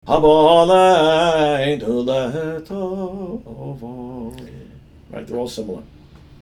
This phrase has a triumphant character, which is appropriate for “Haboh Aleinu L’Tova,” “May it come upon us for good,” and for use as a typical end of sentence motif.